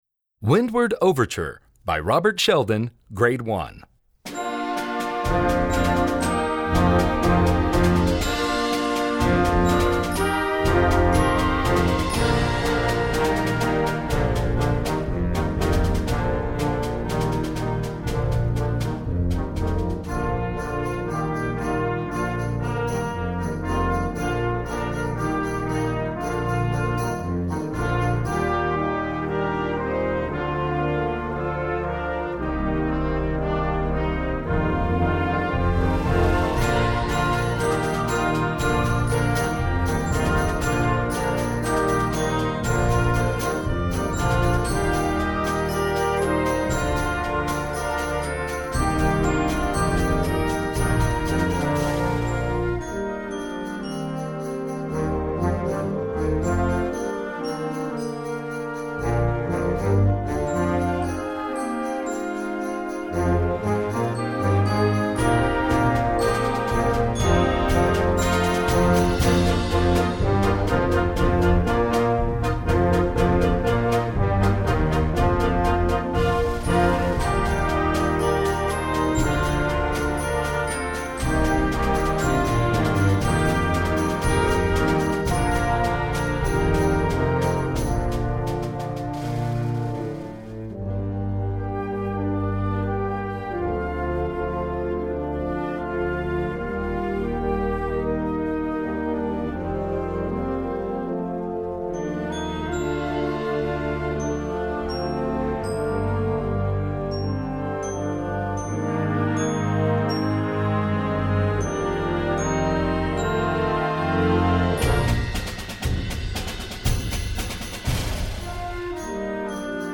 Gattung: Ouvertüre für Jugendblasorchester
Besetzung: Blasorchester